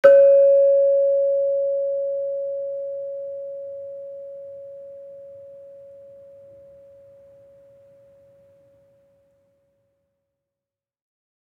Gender-1-C#4-f.wav